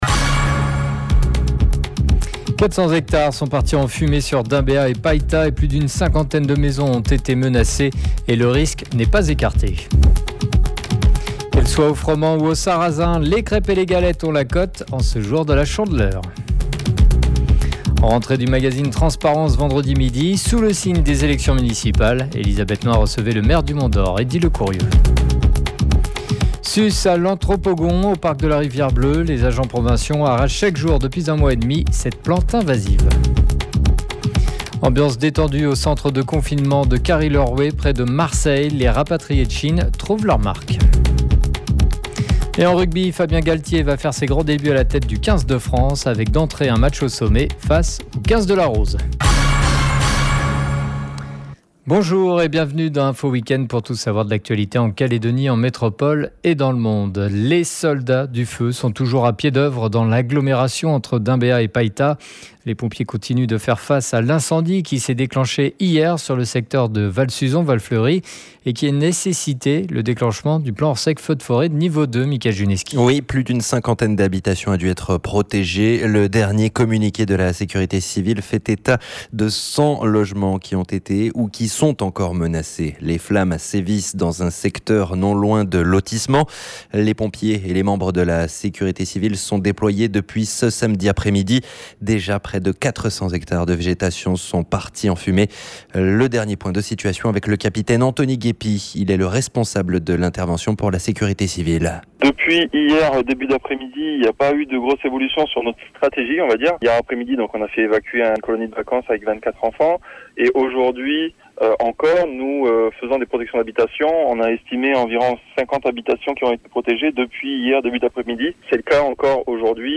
JOURNAL : 02 02 20 ( MIDI )